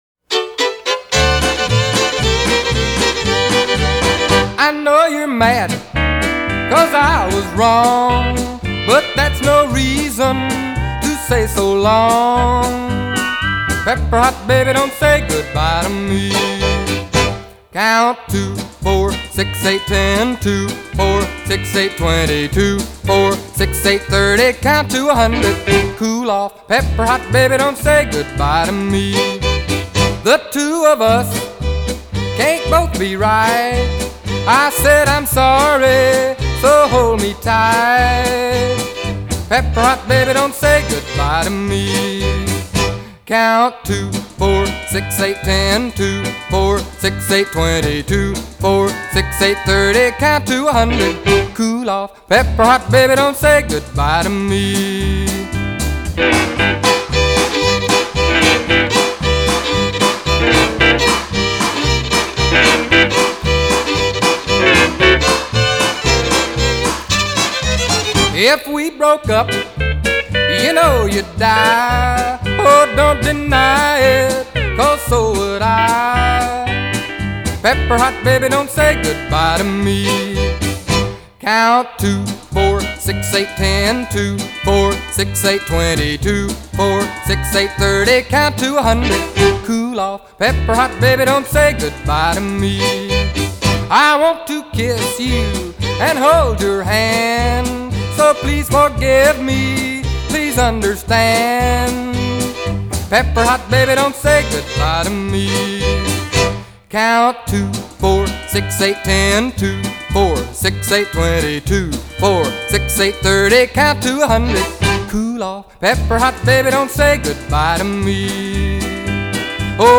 The styles are diverse, mixing Country & Rockabilly.